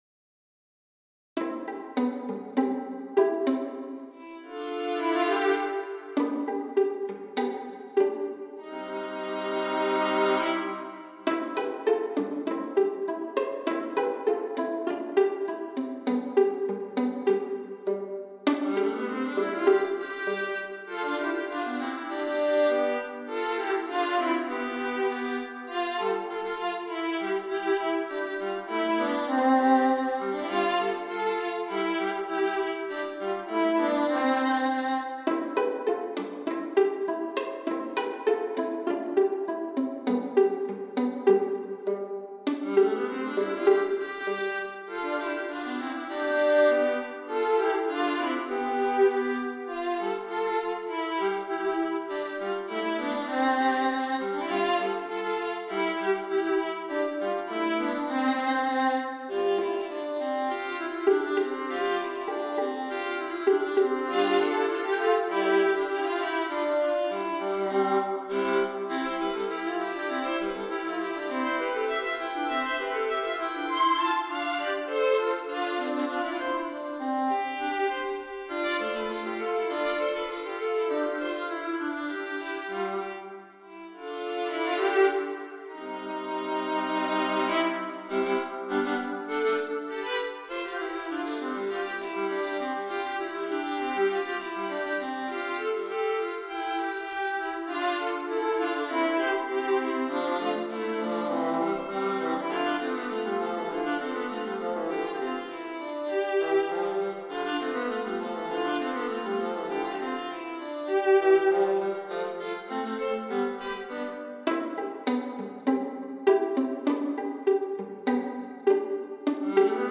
For this treatment of the melody only, the lyrics are not generally pertinent.
2 pages, circa 2' 30" - an MP3 demo is here: